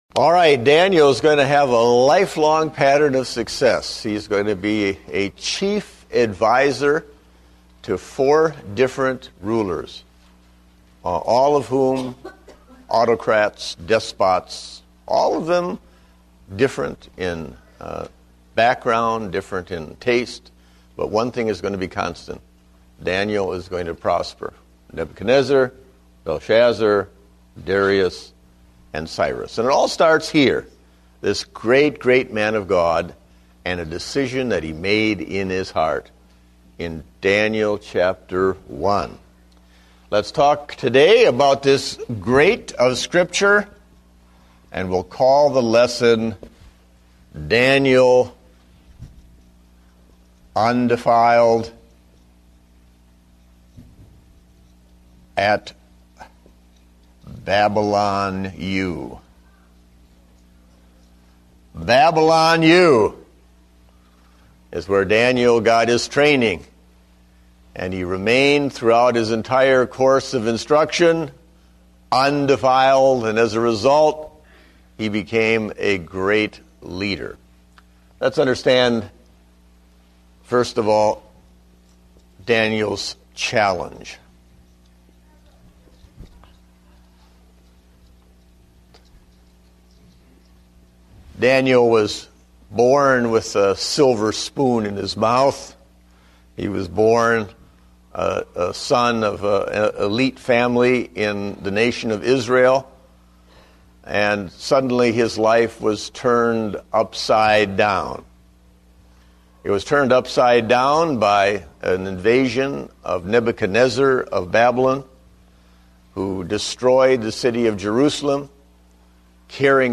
Date: December 6, 2009 (Adult Sunday School)